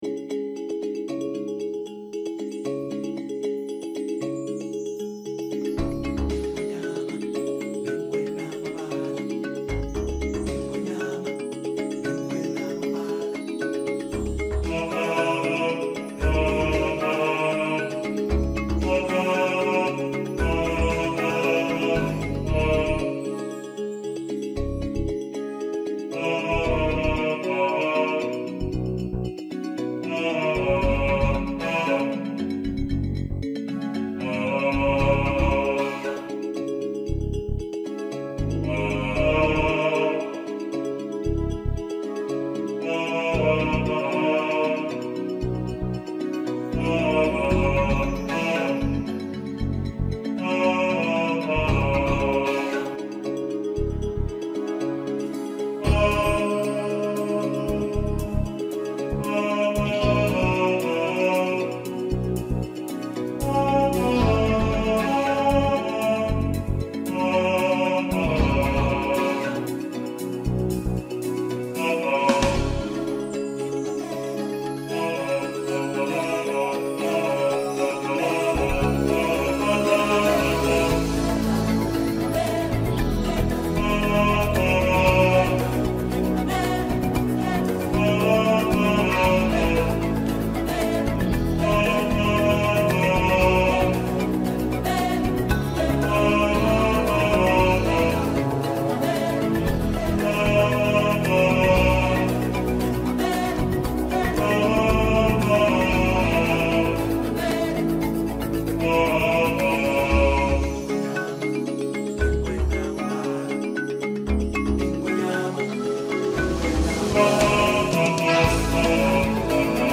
He Lives In You – Bass | Ipswich Hospital Community Choir